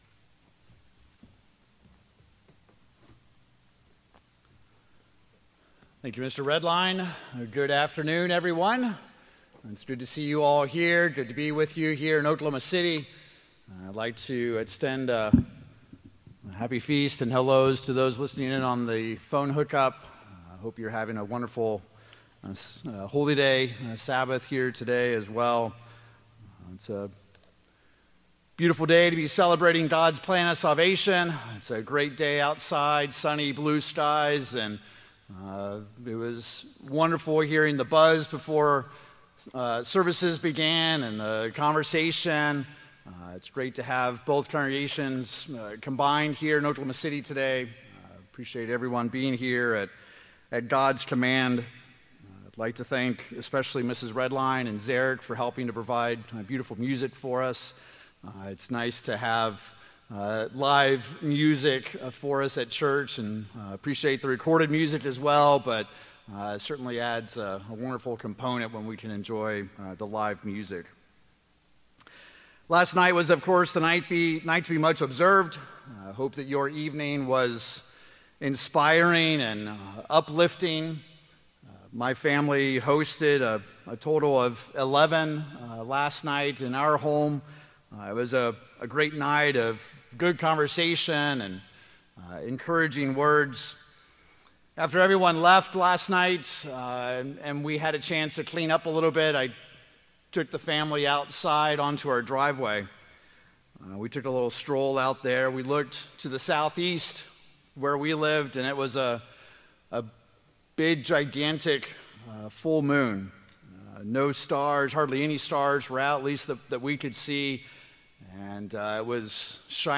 In this Holy Day sermon, we will examine the part you and I are responsible for in conquering our sins.